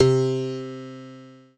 PIANO5-05.wav